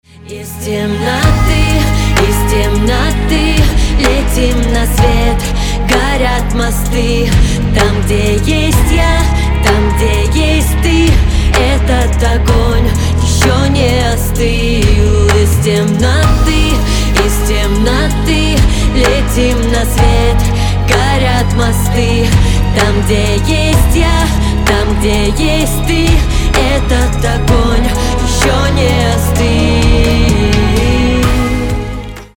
• Качество: 320, Stereo
грустные
спокойные
пианино
красивый женский голос